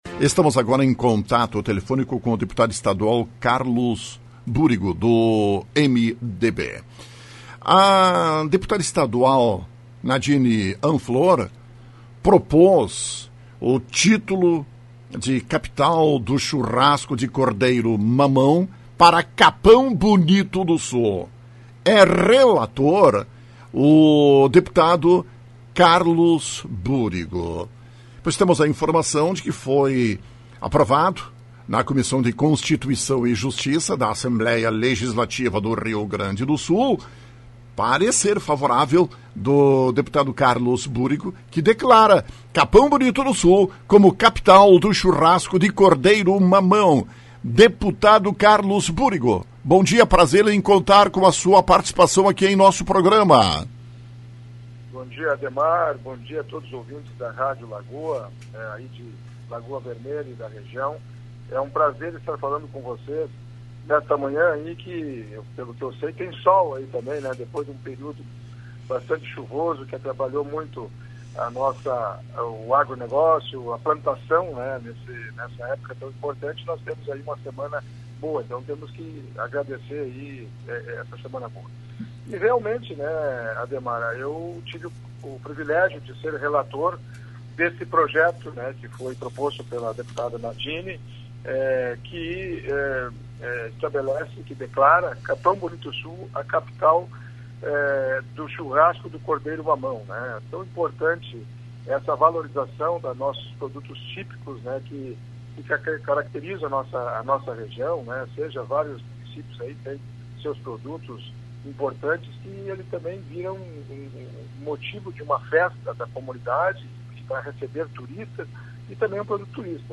Foi aprovado na Comissão de Constituição e Justiça da Assembleia Legislativa do Rio Grande do Sul parecer favorável do deputado Carlos Búrigo que declara Capão Bonito do Sul como Capital do Churrasco de Cordeiro Mamão. Rádio Lagoa FM conversou com o deputado Carlos Búrigo